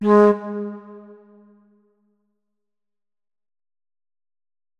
flute